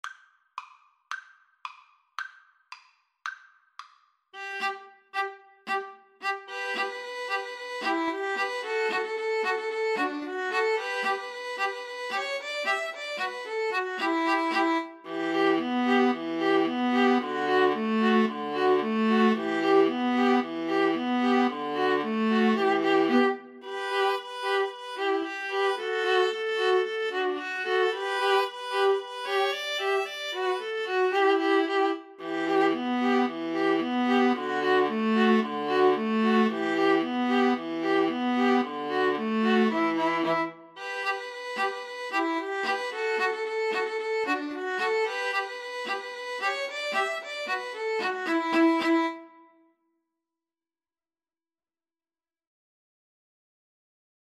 E minor (Sounding Pitch) (View more E minor Music for String trio )
Allegro moderato = c. 112 (View more music marked Allegro)
2/4 (View more 2/4 Music)
String trio  (View more Easy String trio Music)